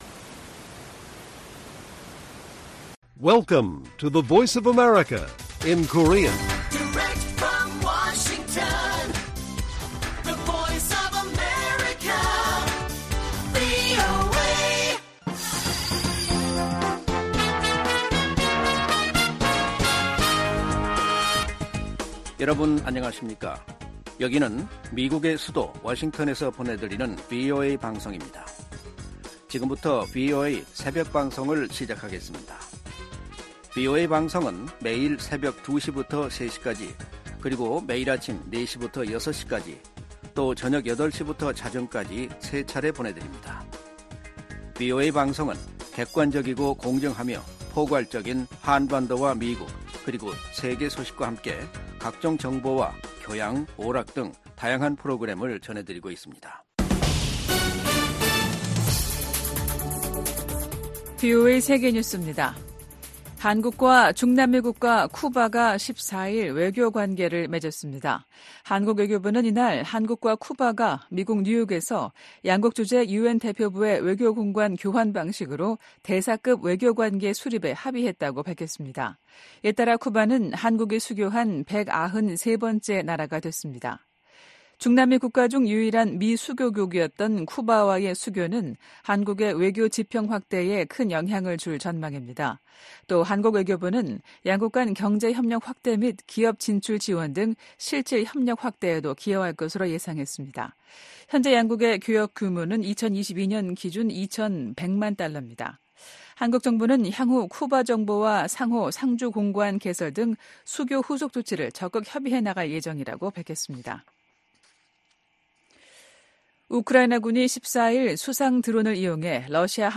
VOA 한국어 '출발 뉴스 쇼', 2024년 2월 15일 방송입니다. 북한이 또 동해상으로 순항미사일을 여러 발 발사했습니다. 미 국무부는 북한이 정치적 결단만 있으면 언제든 7차 핵실험을 감행할 가능성이 있는 것으로 판단하고 있다고 밝혔습니다. 백악관이 북한의 지속적인 첨단 무기 개발 노력의 심각성을 지적하며 동맹 관계의 중요성을 강조했습니다.